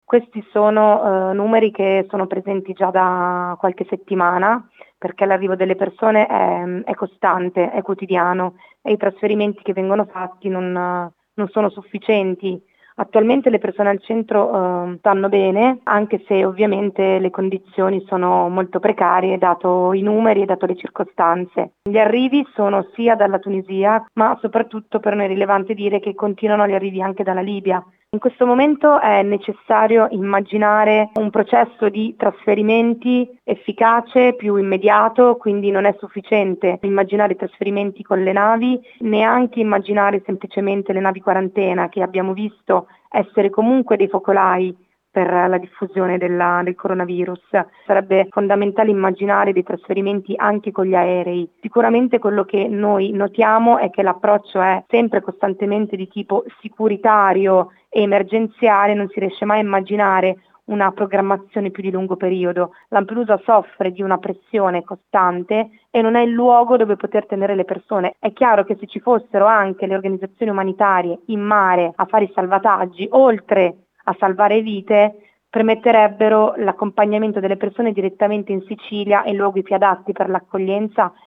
Il racconto della giornata di sabato 22 agosto 2020 attraverso le notizie principali del giornale radio delle 19.30, dai dati dell’epidemia in Italia alla chiusura dei termini per la presentazione delle liste per le prossime amministrative e regionali.